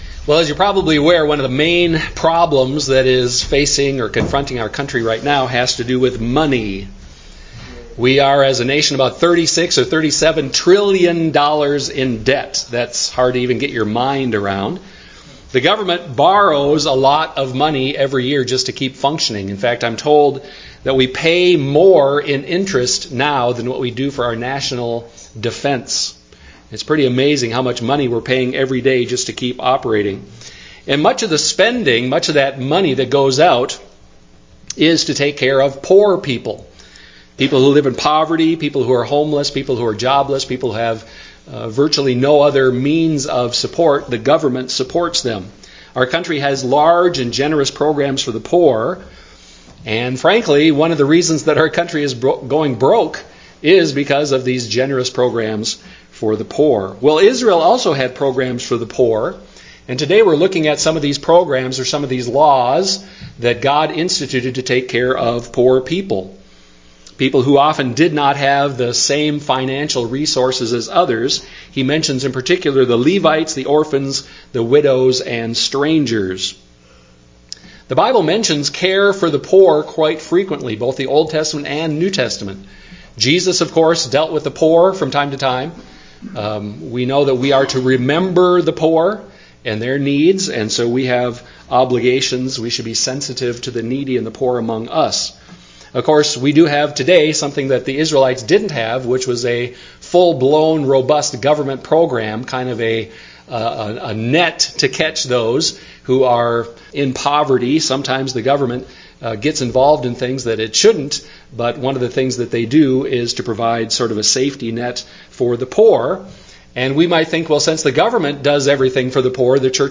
Deuteronomy 14:22-15:18 Service Type: Sunday morning worship service One of the main problems in our country today has to do with money.